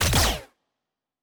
Weapon 07 Shoot 3.wav